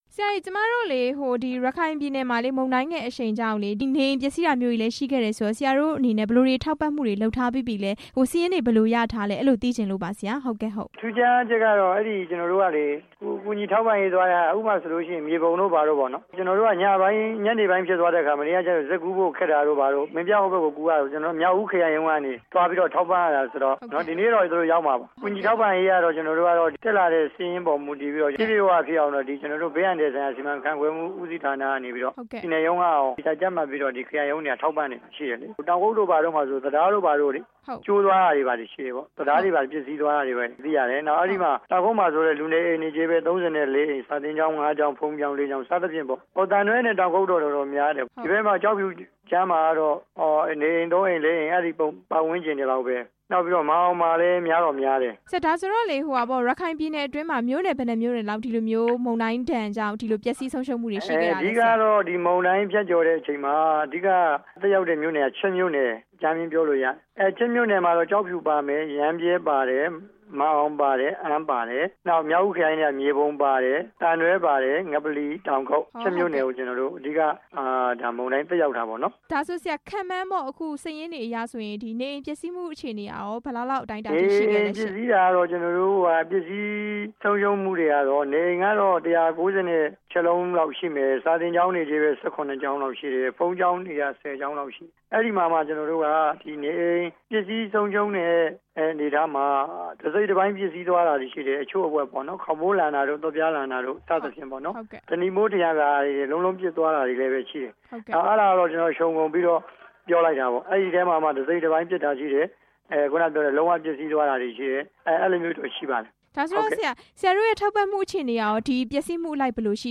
လေပြင်းတိုက်ခတ်ခဲ့တဲ့ ရခိုင်ပြည်နယ်က အခြေအနေအကြောင်း မေးမြန်းချက်